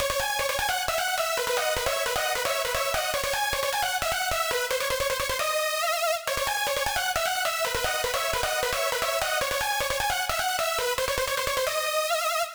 シンセリフパート
今回はその内、よく王道として採用されるシンセブラスでリフを組みました。
まあ分かりやすくユーロらしい音ですね！